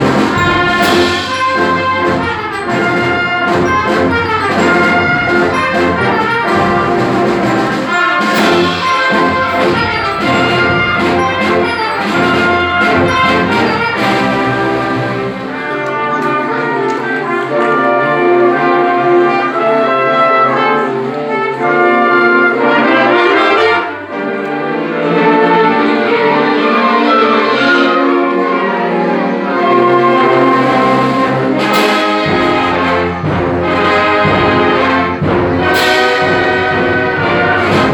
Suite aux différents ateliers, c’était au tour de l’harmonie de l’école Montcalm de faire une prestation.